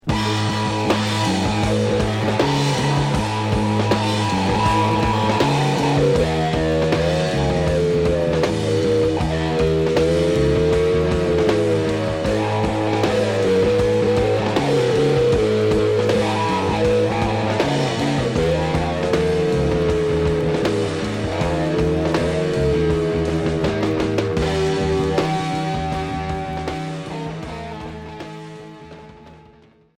Heavy rock Premier 45t retour à l'accueil